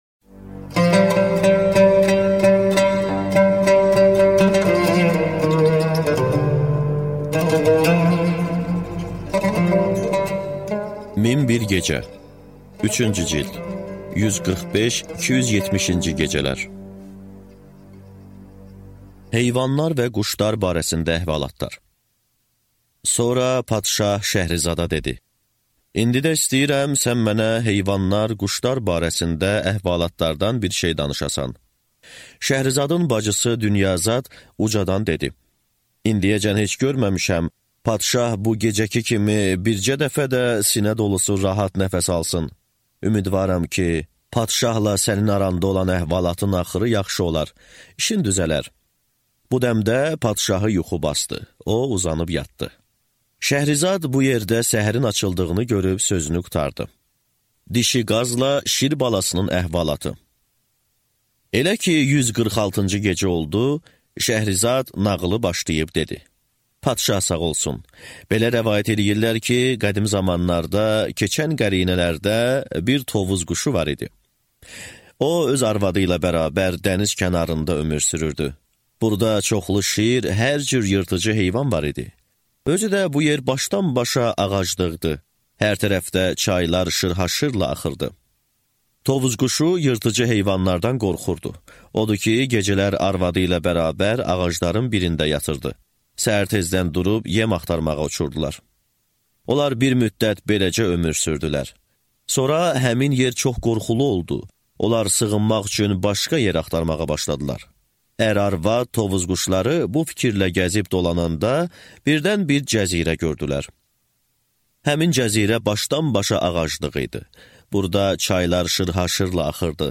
Аудиокнига Min bir gecə 3-cü cild | Библиотека аудиокниг